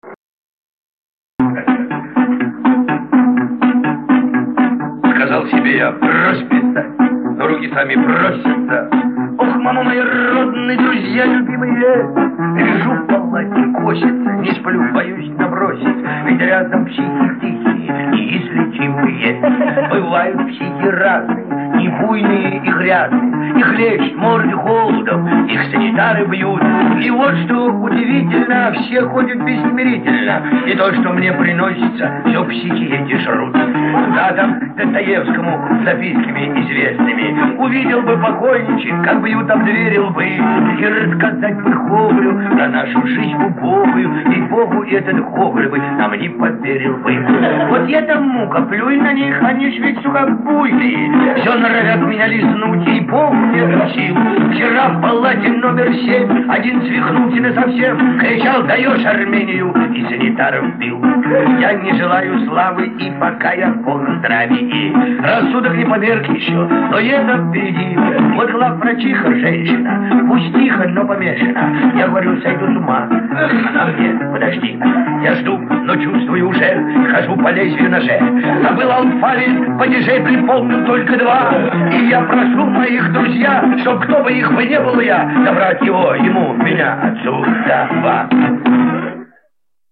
Бардовские песни Авторские песни